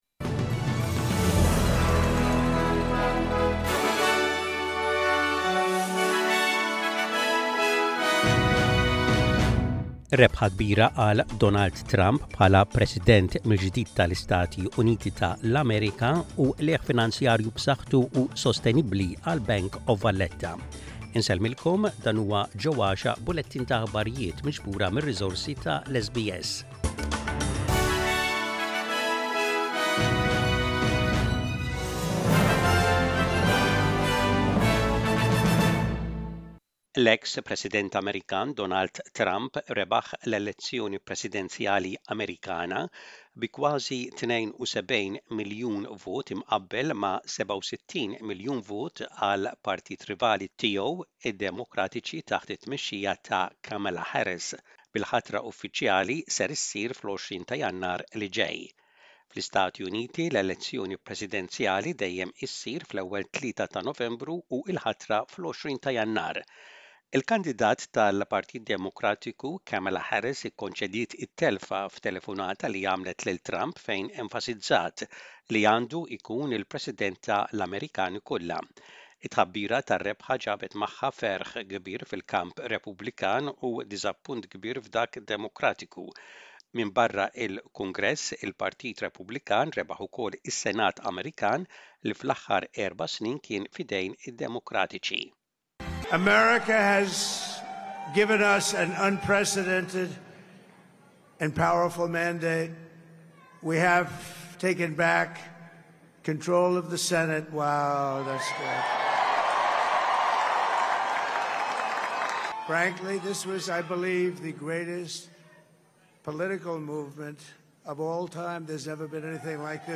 SBS Radio | Aħbarijiet bil-Malti: 08.11.24